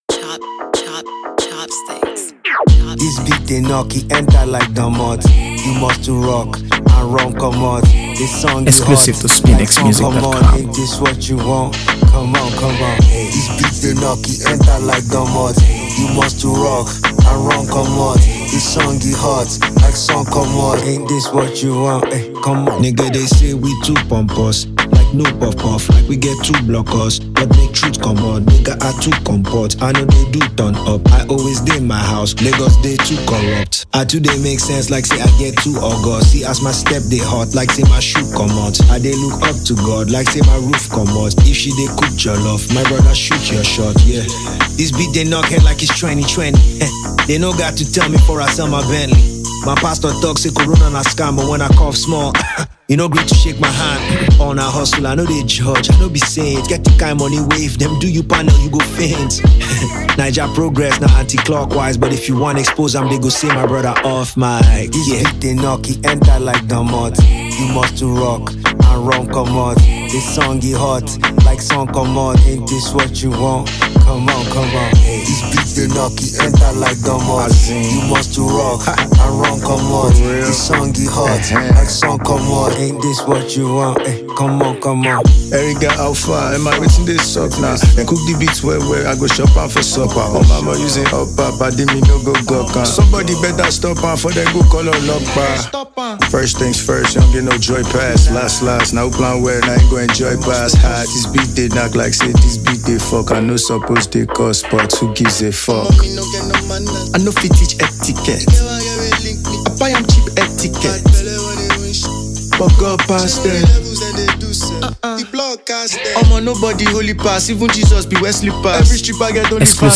rapper
featuring hip-hop head